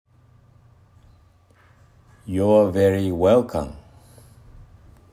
より自然なカタカナ ヨア　ヴェリー　ウェウカン！